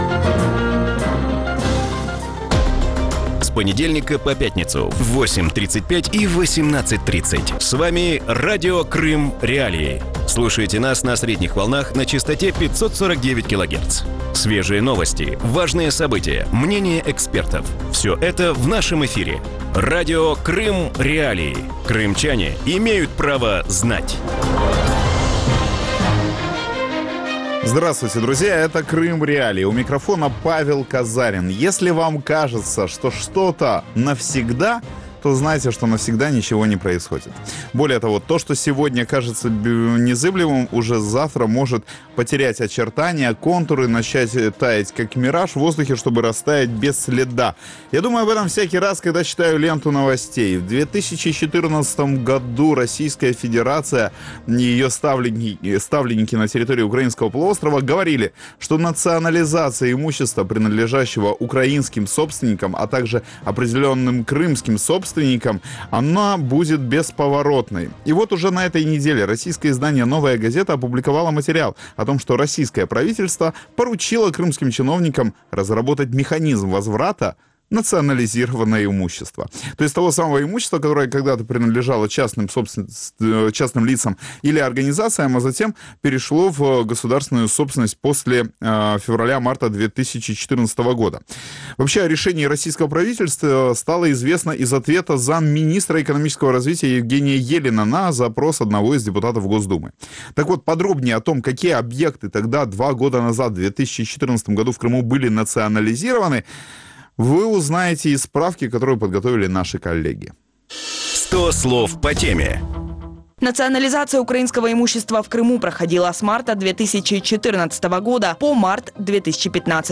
В вечернем эфире Радио Крым.Реалии обсуждают обращение российского правительства к крымским чиновникам о пересмотре итогов национализации.